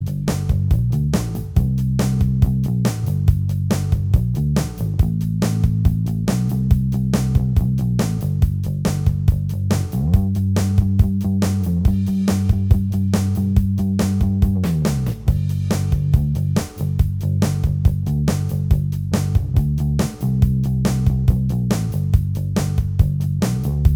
Minus Guitars Soft Rock 3:30 Buy £1.50